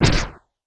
etfx_shoot_rocket.wav